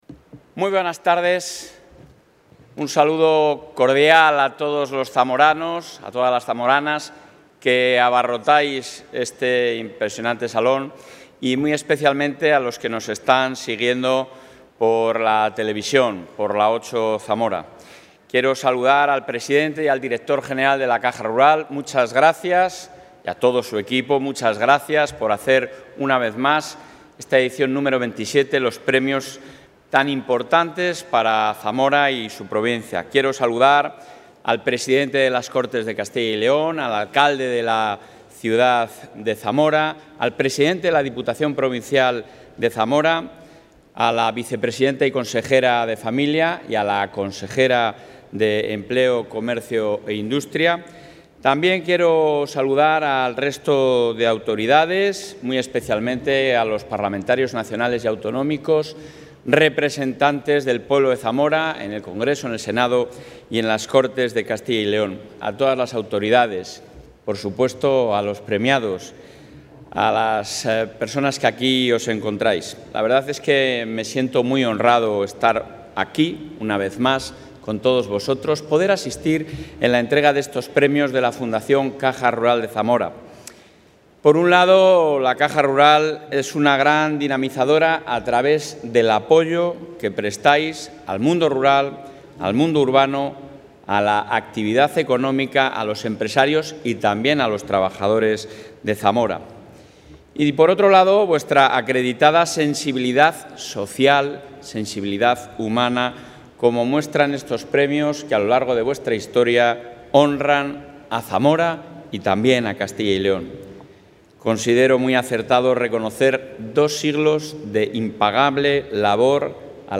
Intervención del presidente de la Junta.
El presidente de la Junta de Castilla y León, Alfonso Fernández Mañueco, ha participado en la gala de los XXVII Premios Fundación Caja Rural de Zamora, donde ha destacado el dinamismo y el impulso que está experimentando esta provincia. Este avance, como ha indicado, se manifiesta en diferentes proyectos que están en marcha y que permitirán crear empleo y oportunidades de futuro.